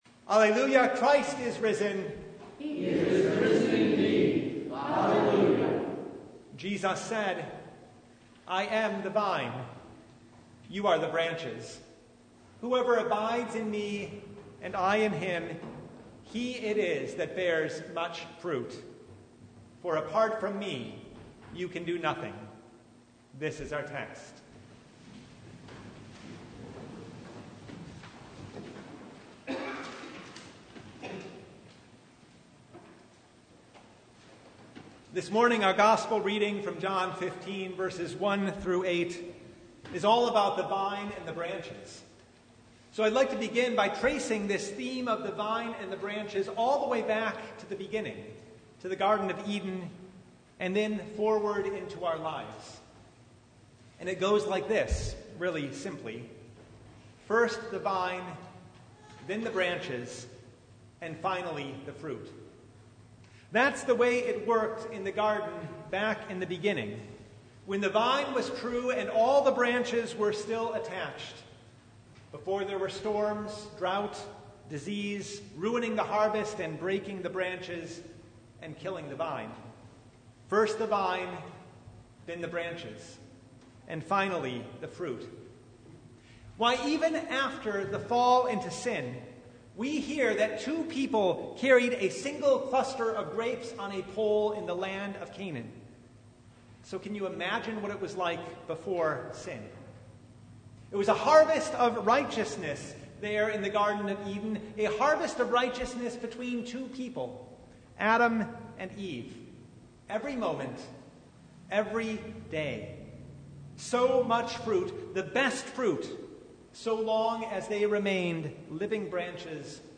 John 15:1-8 Service Type: Sunday First the vine